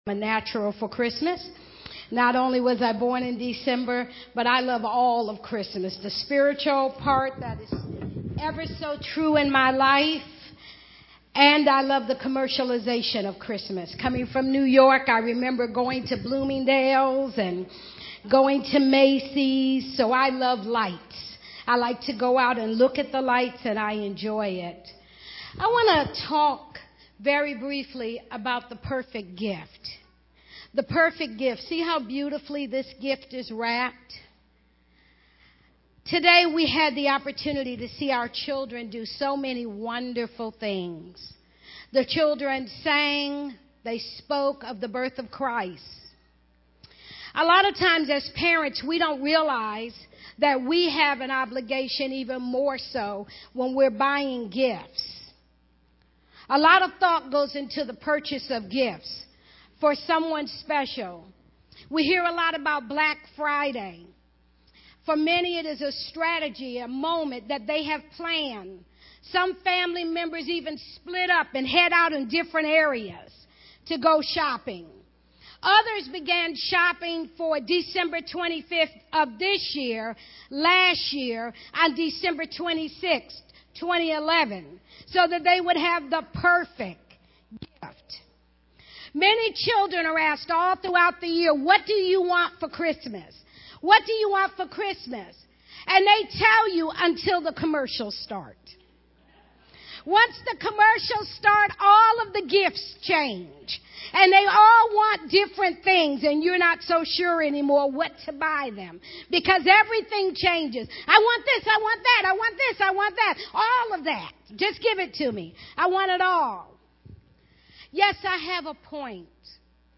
The Perfect Gift (Children's Program Sunday)